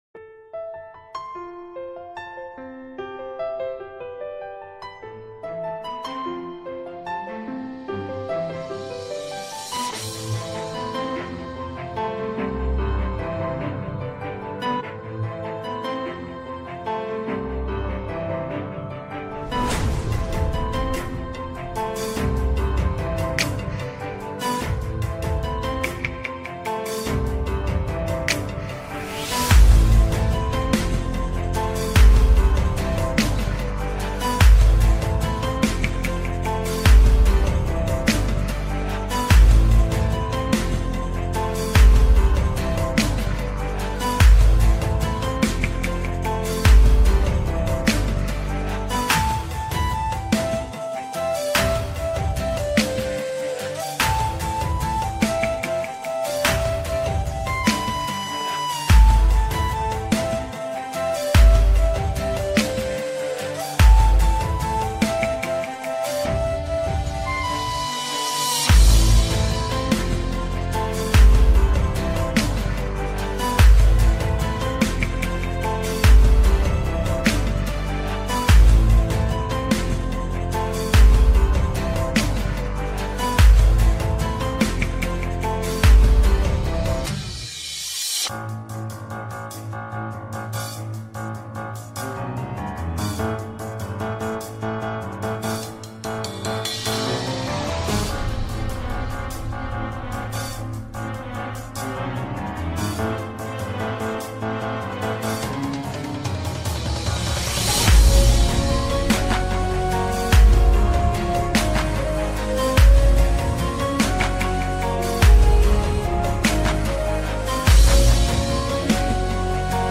Спокойная красивая мелодия без слов